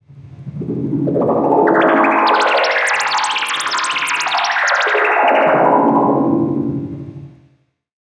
Vintage Bubbler.wav